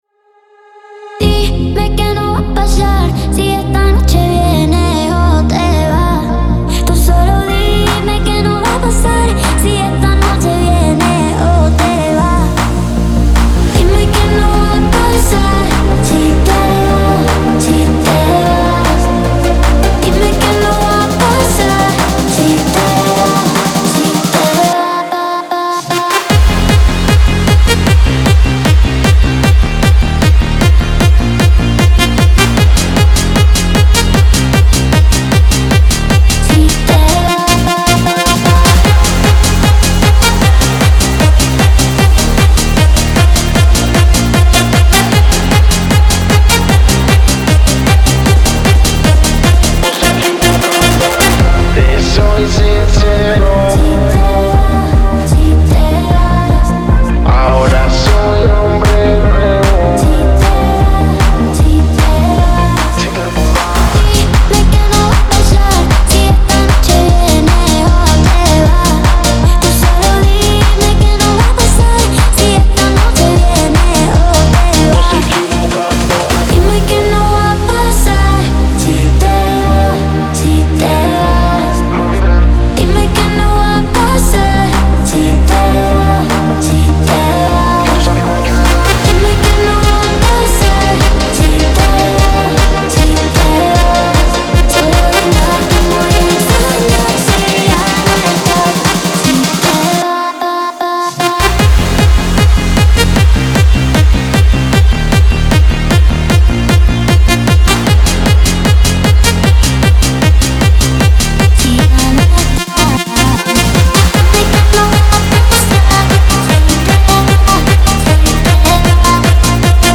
• Жанр: Electronic, House